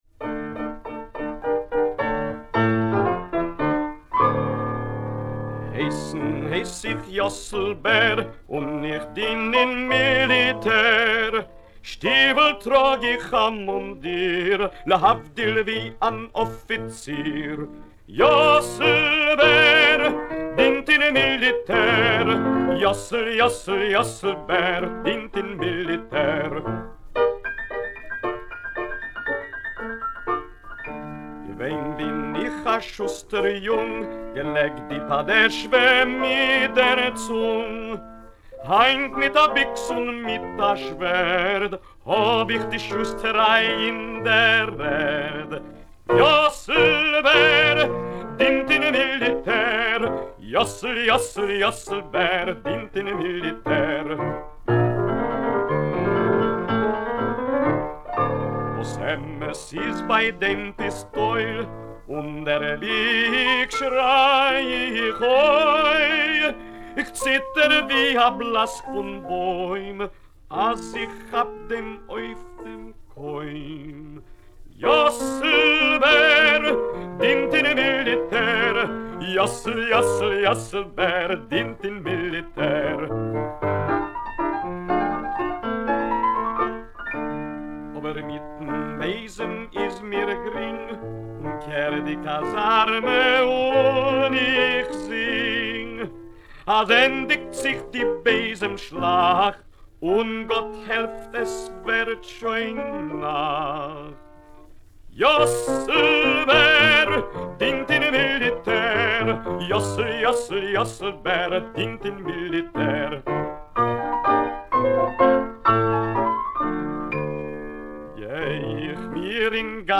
Musique des cabarets yiddish, musique israélienne...